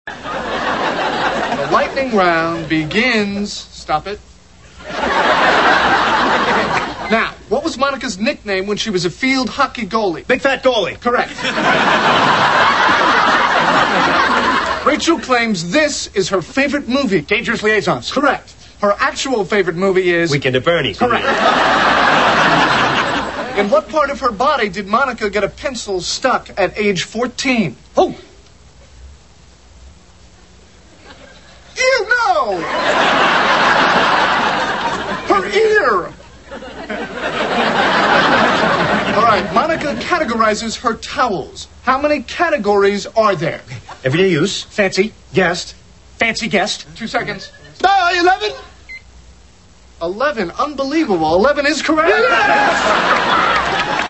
These are .mp3 soundbites from the NBC television show "Friends."